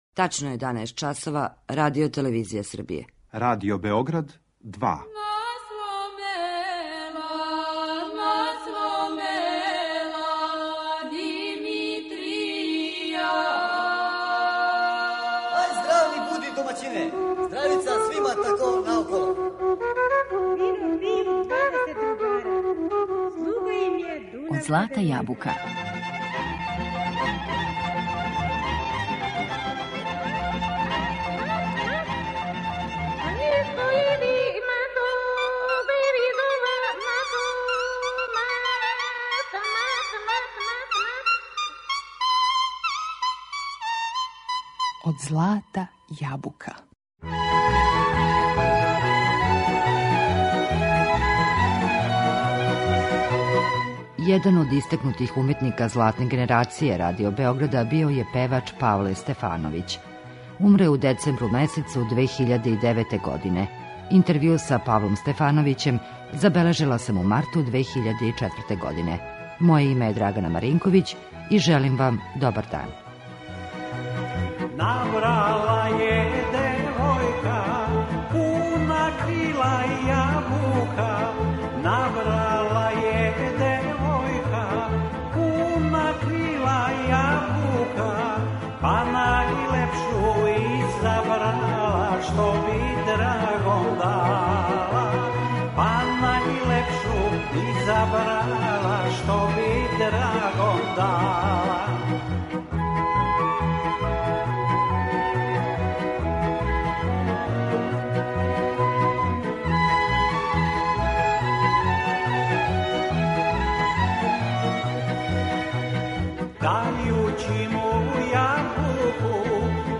традиционалне народне музике